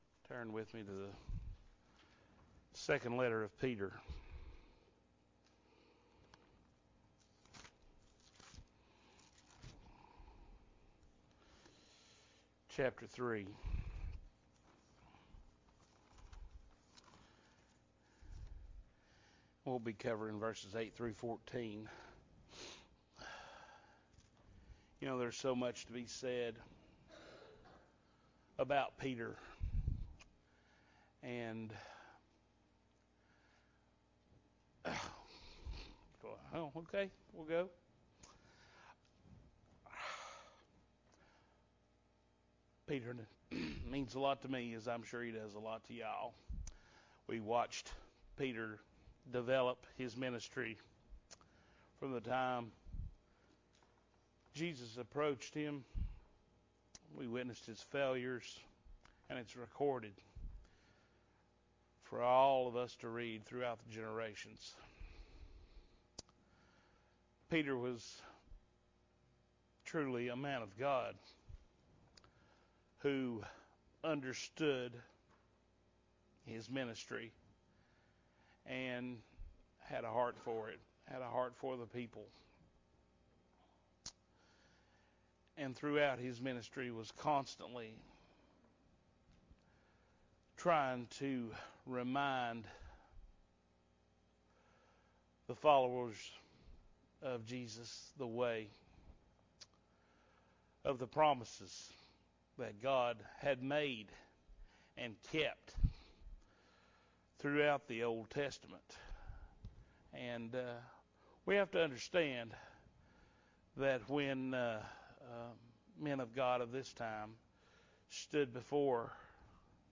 December 5, 2021 – Morning Worship